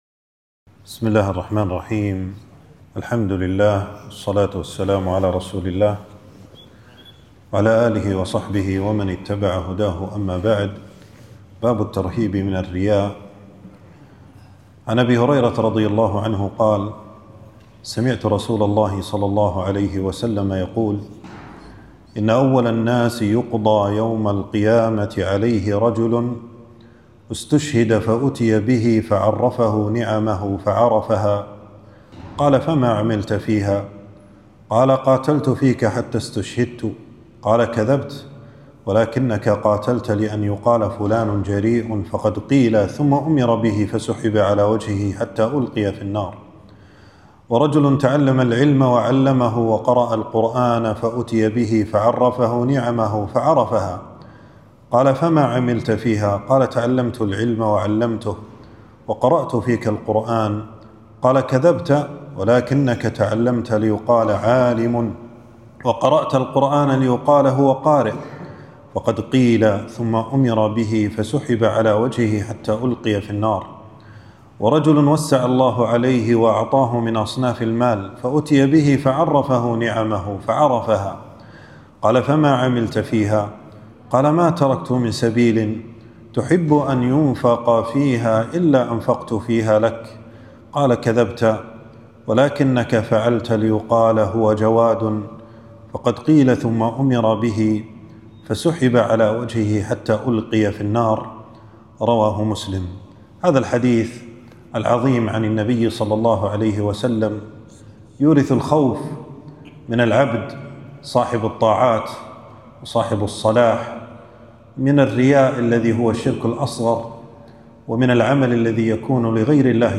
في مسجد أبي سلمة بن عبدالرحمن.